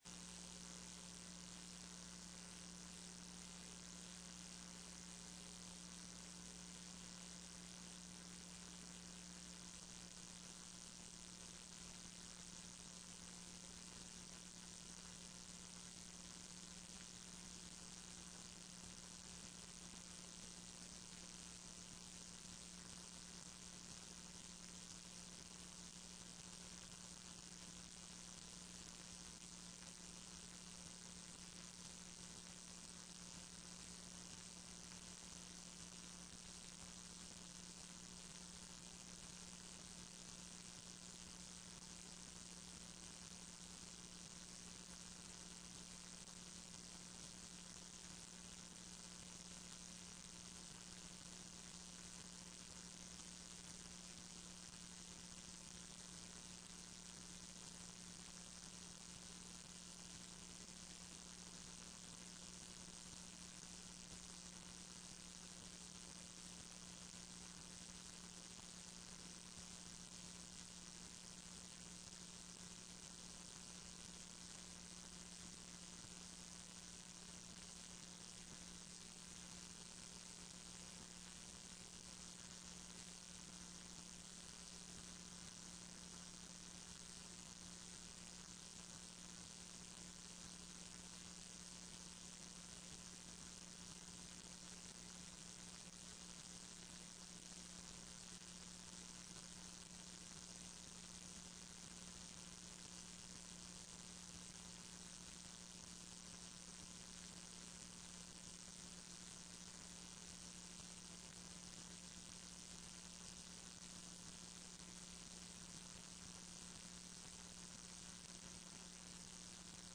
TRE-ES - Áudio sessão 13.10